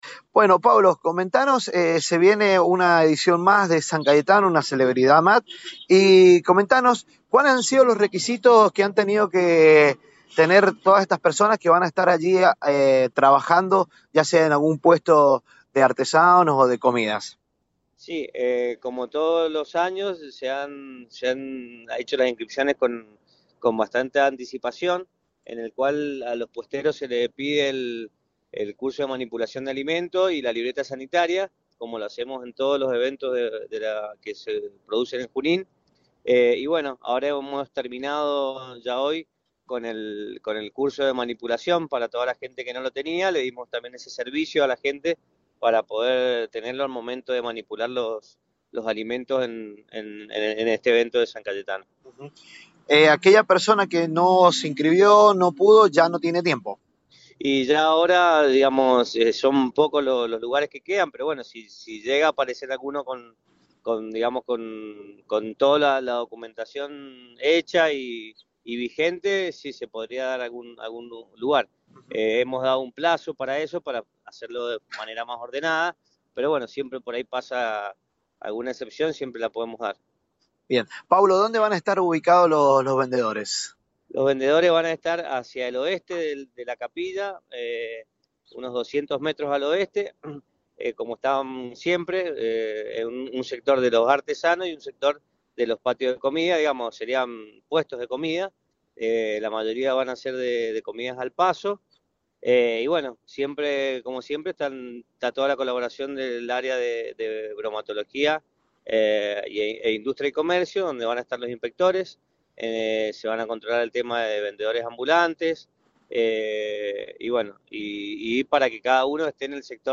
En los microfonos de Proyección 103″, el programa radial de todas las mañanas, conocimos un poco sobre los detalles sobre una nueva peregrinación por el día del Santo.